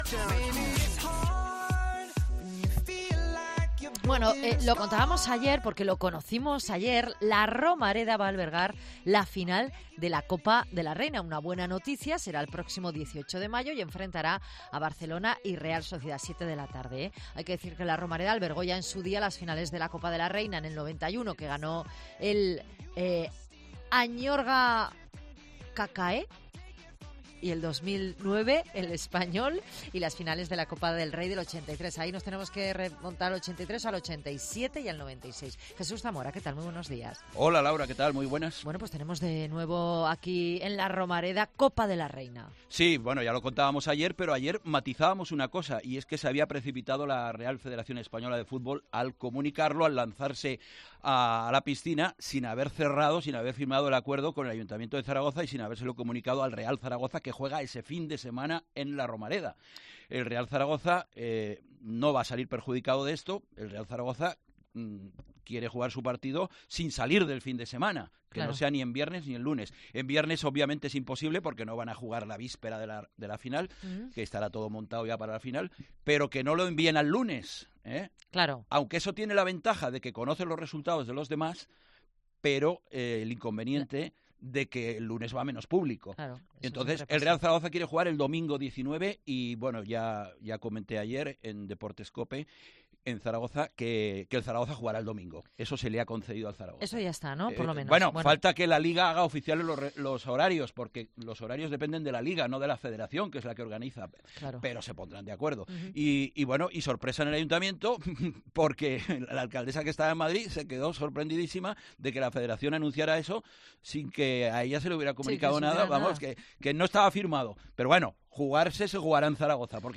Entrevista a Cristina García, Directora General de Deportes del Gobierno de Aragón, en COPE Zaragoza